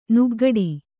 ♪ nuggaḍi